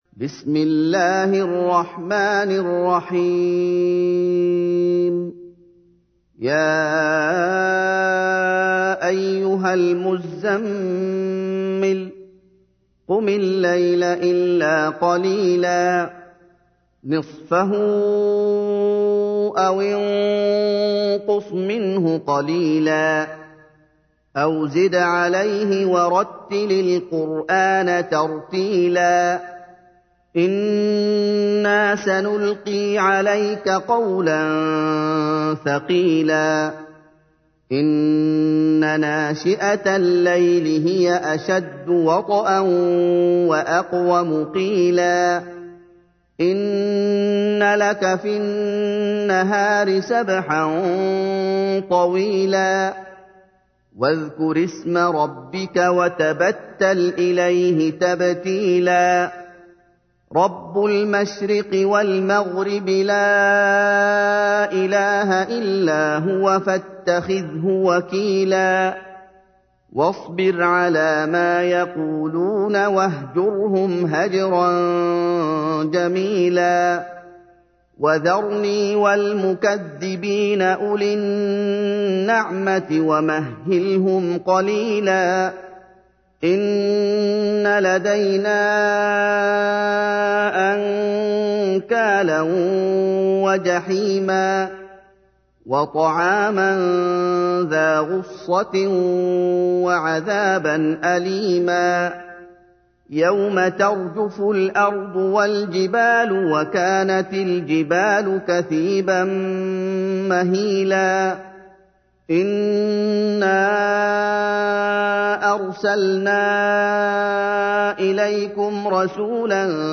تحميل : 73. سورة المزمل / القارئ محمد أيوب / القرآن الكريم / موقع يا حسين